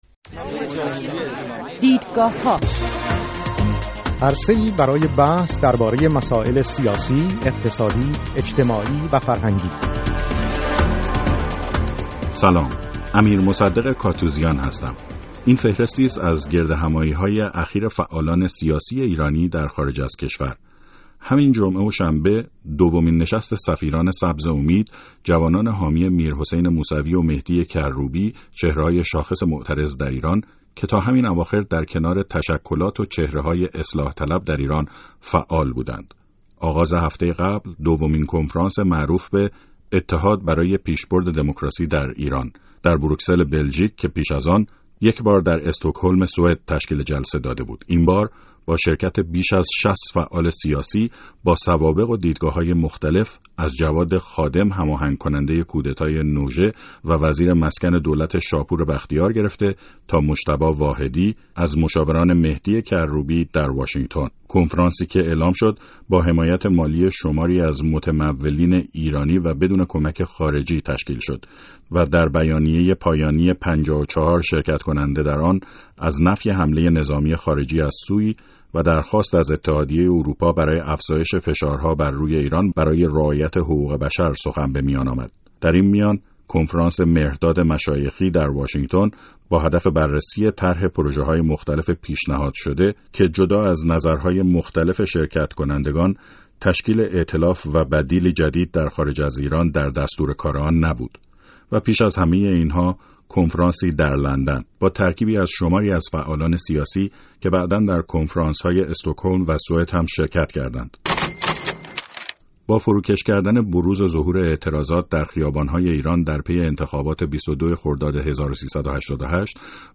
در برنامه این هفته دیدگاه‌ها سه میهمان به دوگانگی در میان منتقدان و مخالفان حکومت ایران بر سر بدیل‌سازی و کسب حمایت خارجی پرداخته‌اند.
مناظره